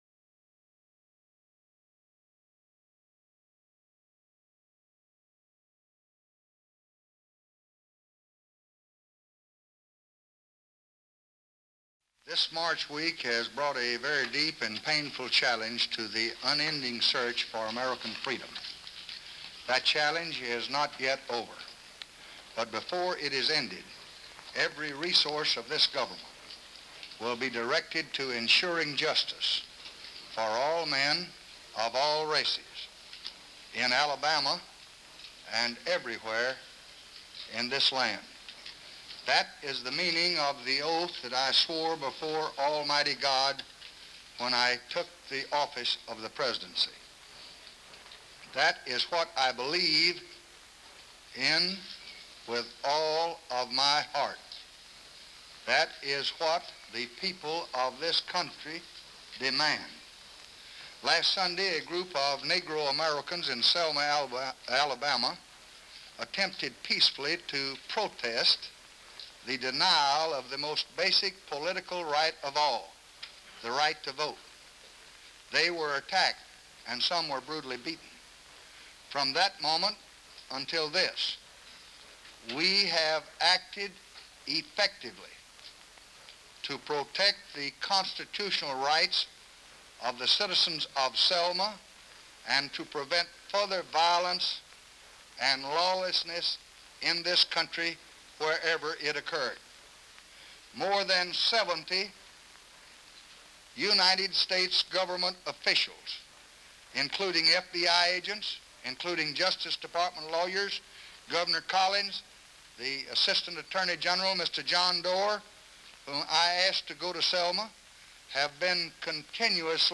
March 13, 1965: Press Conference at the White House